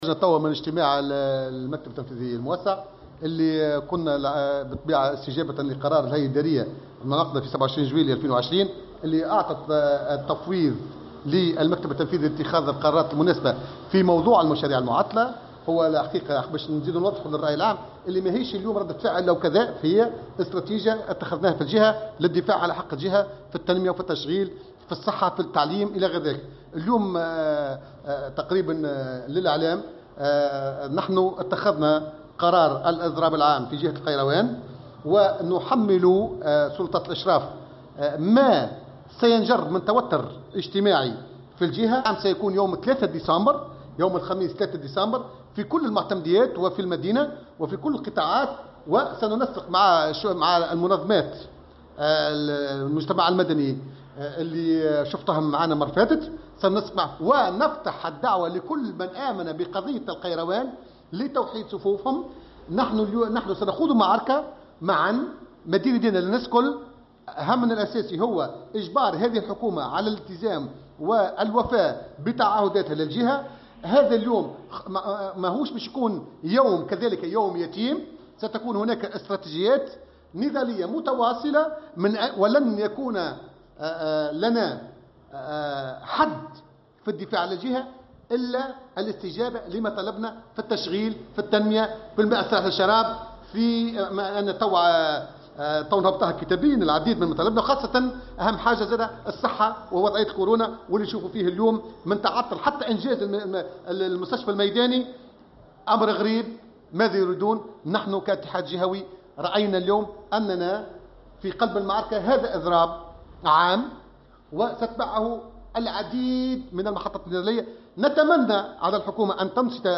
وأضاف في تصريح لمراسل "الجوهرة أف أم" أن هذا التحرّك الاحتجاجي يأتي للتنديد بغياب التنمية والتهميش الذي يعاني منه أبناء الجهة، محمّلا سلطة الإشراف ما سينجر عن ذلك من توتّر اجتماعي.